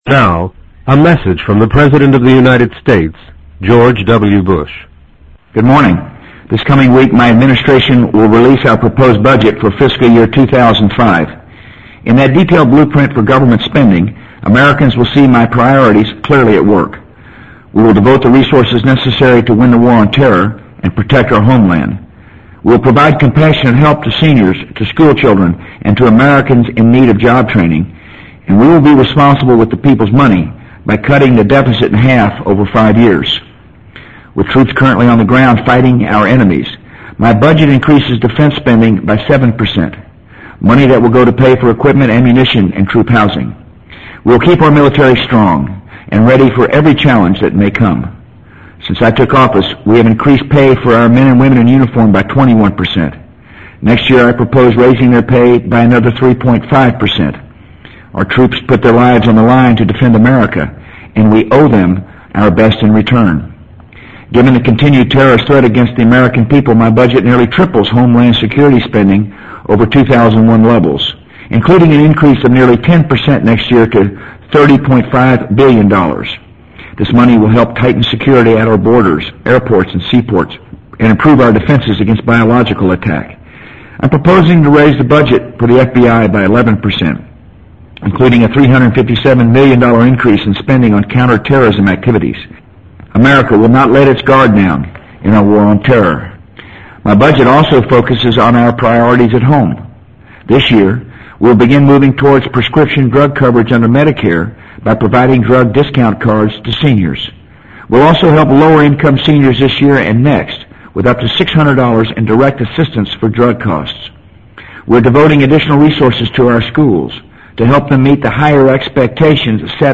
【美国总统George W. Bush电台演讲】2004-01-31 听力文件下载—在线英语听力室